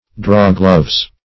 Search Result for " drawgloves" : The Collaborative International Dictionary of English v.0.48: Drawgloves \Draw"gloves`\, n. pl.